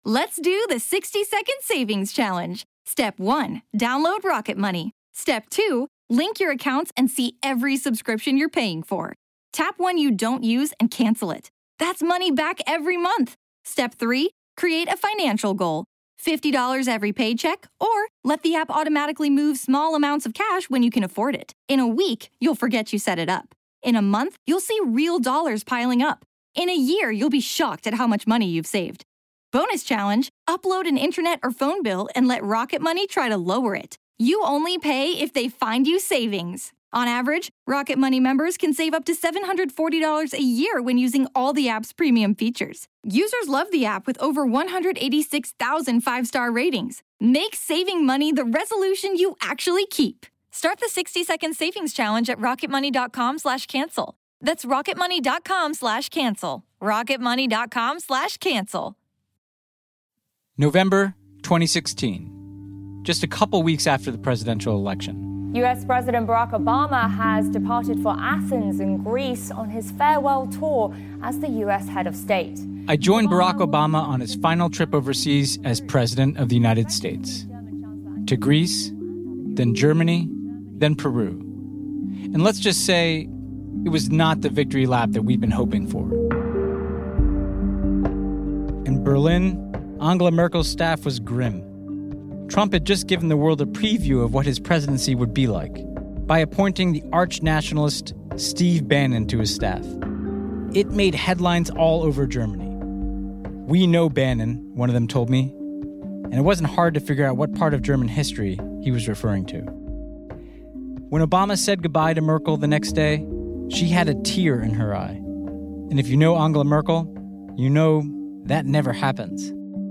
Host Ben Rhodes talks to David Lammy, a member of the UK Parliament, about the forces that gave rise to Brexit and nationalism across the West. Then we hear from European activists who have had success in pushing back, and from Americans about what the U.S. needs to do differently.